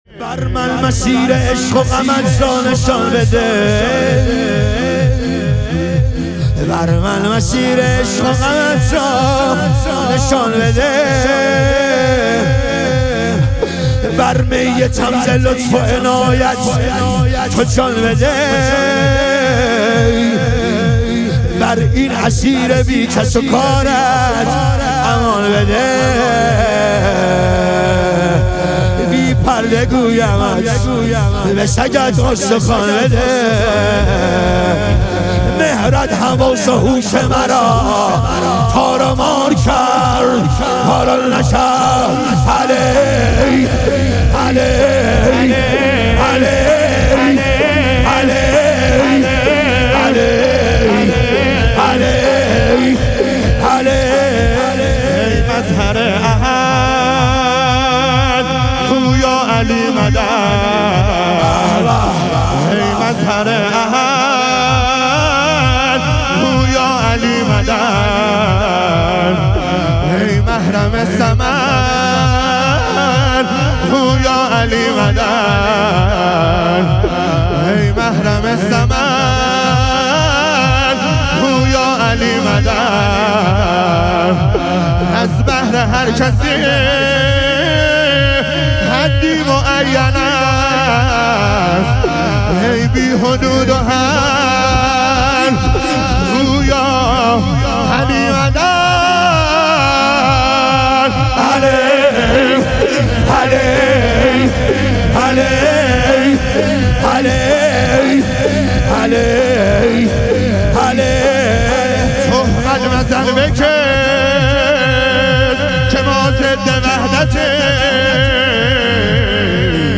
شام غریبان حضرت زهرا علیها سلام - شور